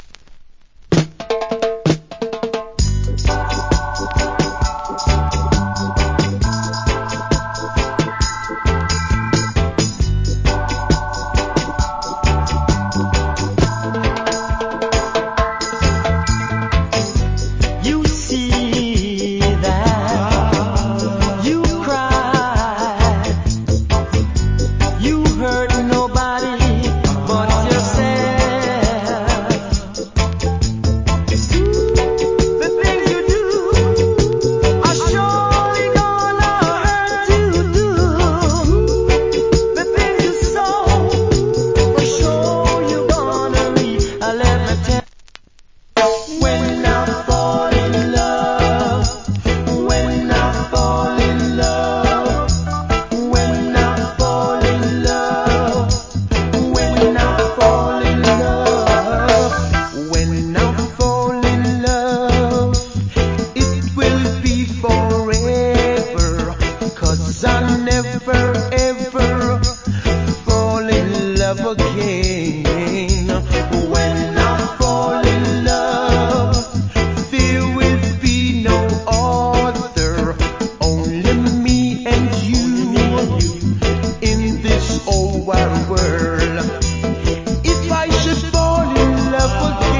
Nice Reggae Vocal + Dub.